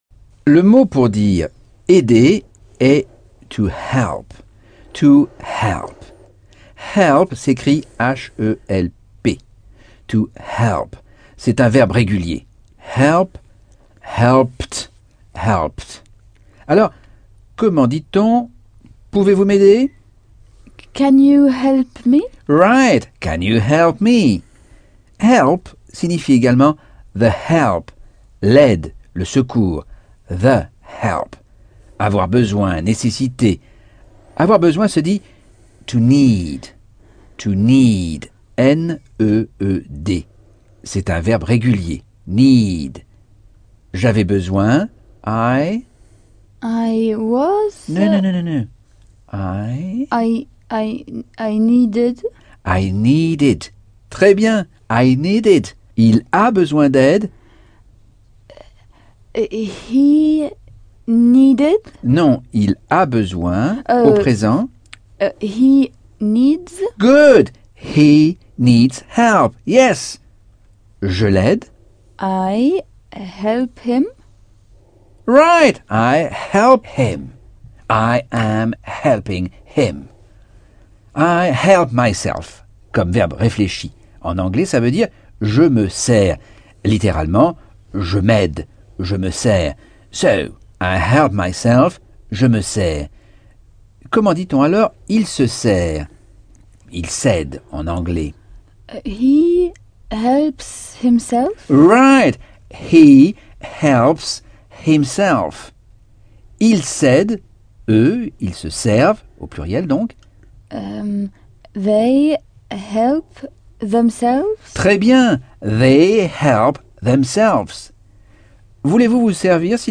Leçon 3 - Cours audio Anglais par Michel Thomas - Chapitre 11